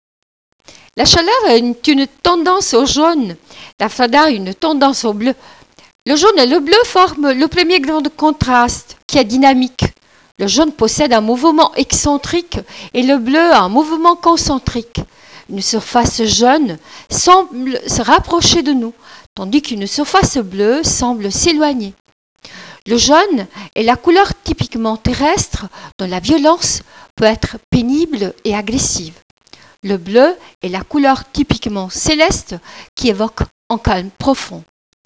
n° 2 lecture expressive, vitesse normal